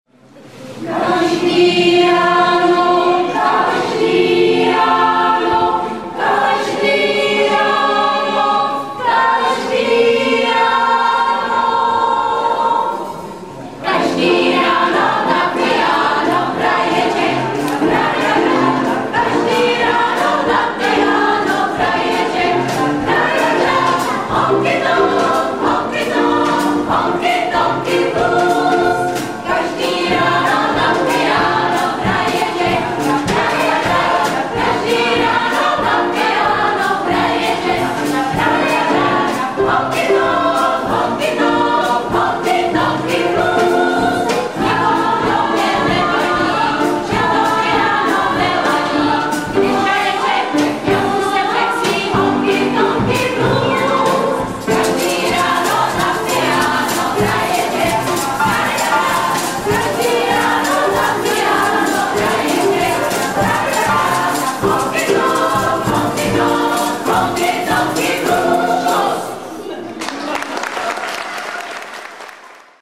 Živě z akce ZUŠ Zliv - 60. léta ze dne 8.11.2007
Sníženou kvalitu zvuku nahrazuje atmosféra